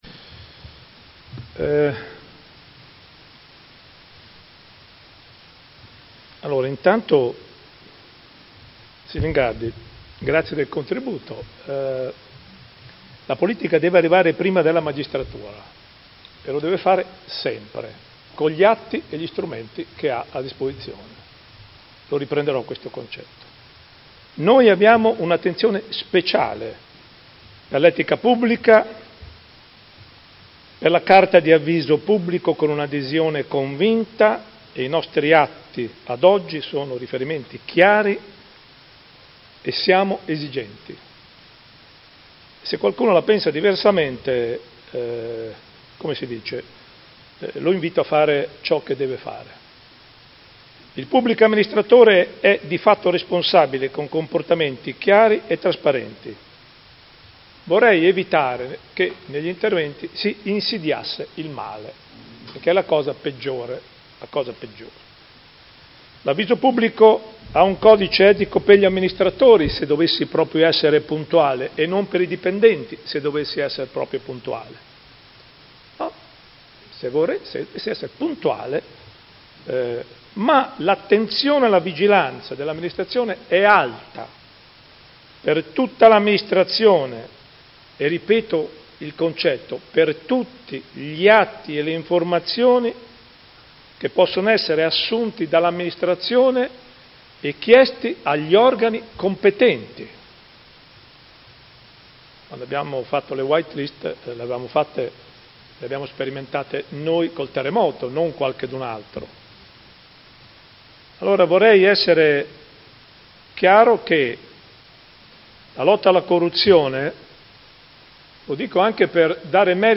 Seduta del 19/12/2019 Replica a dibattitto.